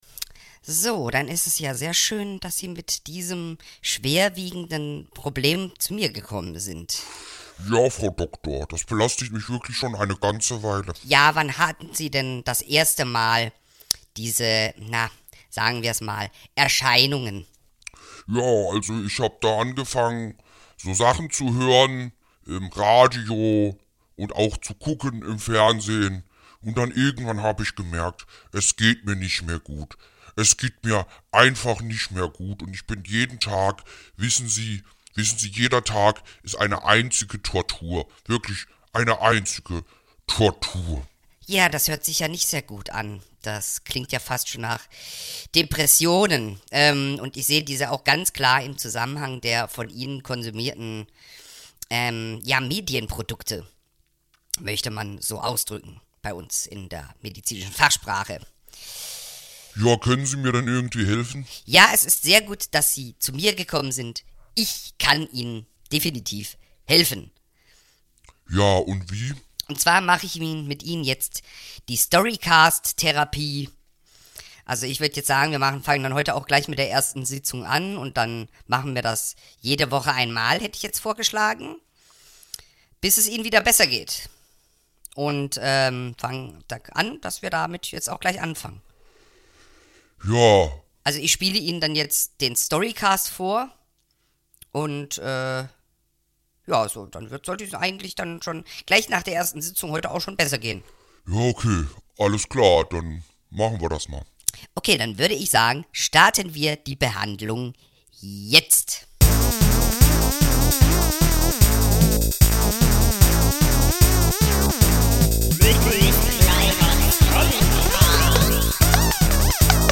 erzählt eine Grusel-Geschichte. Protagonist ist der Schlotternde Schlomo, der ein Dorf in Florahnien heimsucht, um seinem finsteren Wesen freien Lauf lassen zu können.